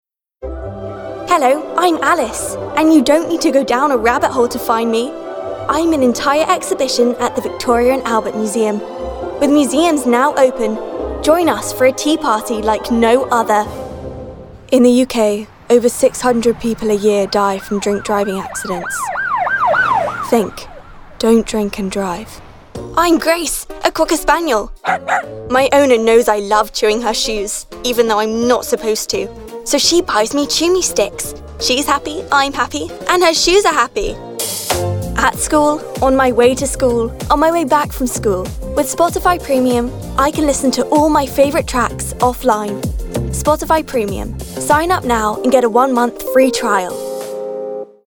UK Voice Reel